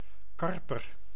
Ääntäminen
Ääntäminen France: IPA: /kaʁp/ Haettu sana löytyi näillä lähdekielillä: ranska Käännös Konteksti Ääninäyte Substantiivit 1. karper {m} eläintiede Suku: m .